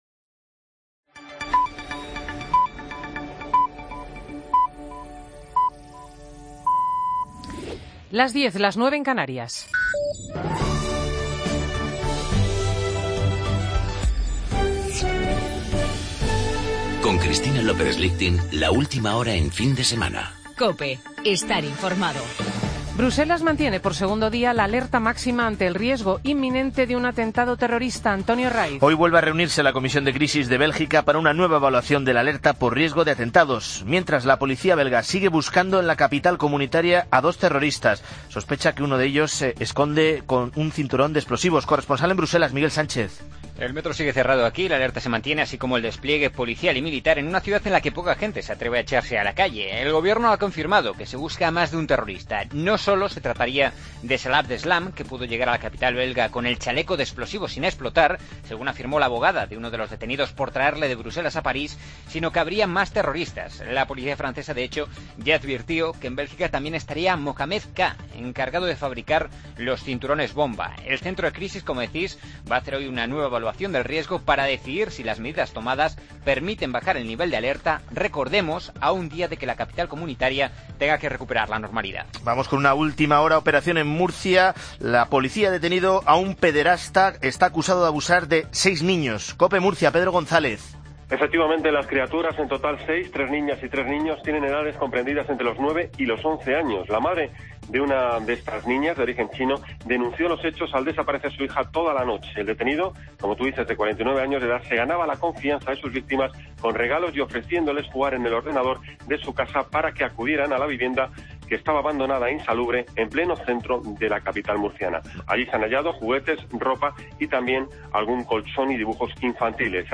AUDIO: Noticias-de-las-10h-del domingo 22 de noviembre de 2015